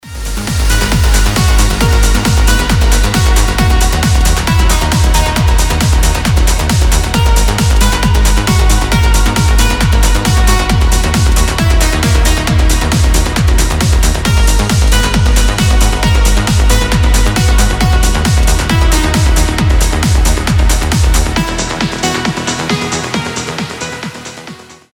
громкие
Electronic
EDM
электронная музыка
без слов
Trance
Uplifting trance
Аплифтинг транс музыка